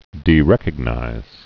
(dē-rĕkəg-nīz)